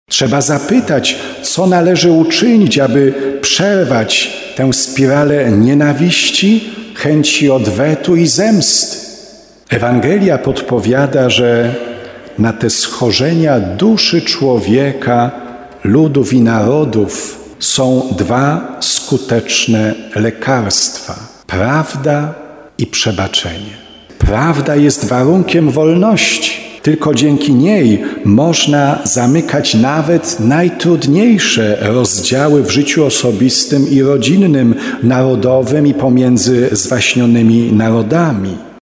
15kazanie.mp3